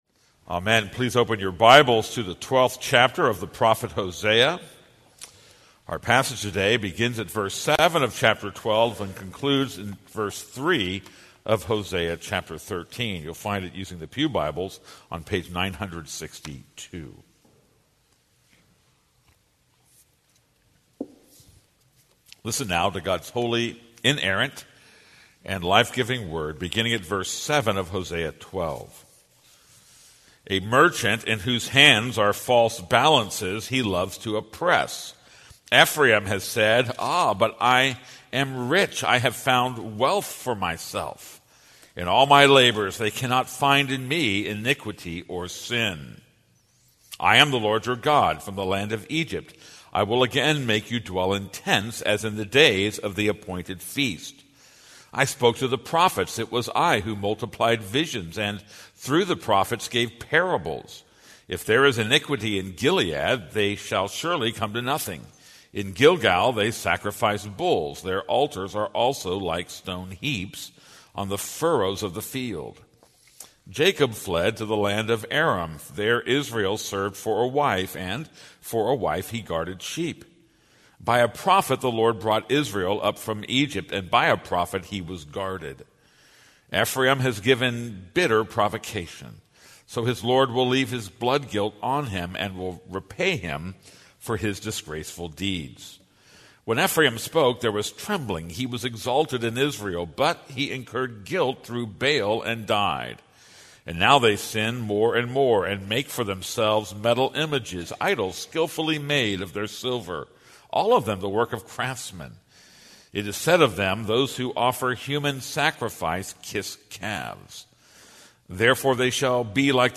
This is a sermon on Hosea 12:7-13:3.